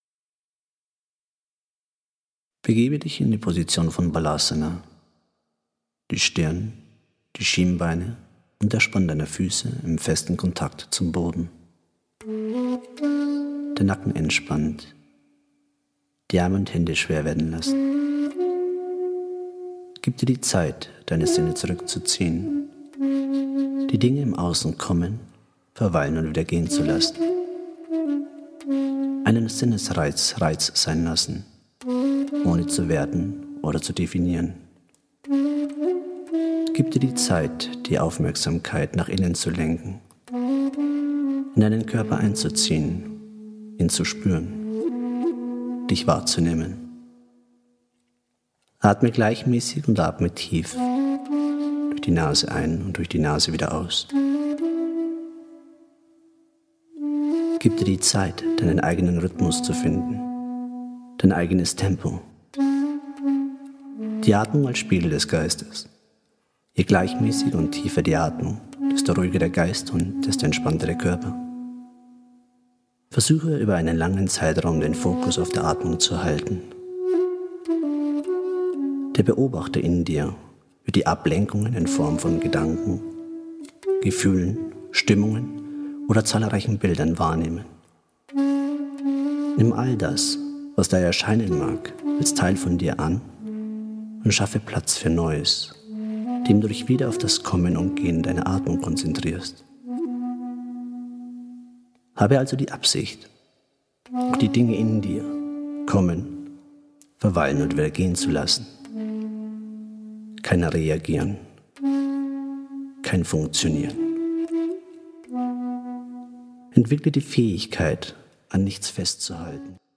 Eine Übungsreihe zur Entspannung mit einer geführten Meditation
Die angenehme und ruhige Stimme des Lehrers begleitet die Übungen und eine innere Ausrichtung von der ersten Asana bis zum Langsam-wieder-in-die-Welt-zurückkehren nach einer geführten Schlussentspannung.
Die ausrichtenden Worte, harmonischen Flötenklänge im Hintergrund und die Momente der Stille lassen in Körper und Geist nach und nach Ruhe einkehren und Platz entstehen für ein inneres Erleben in den Positionen.